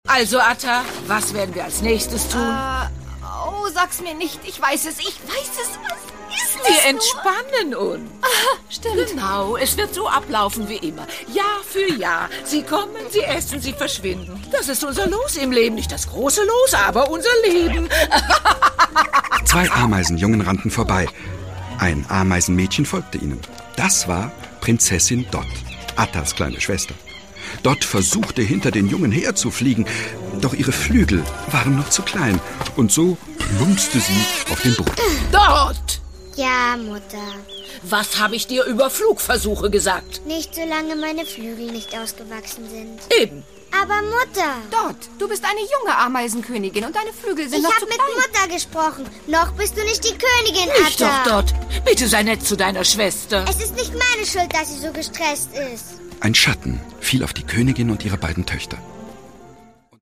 Produkttyp: Hörspiel-Download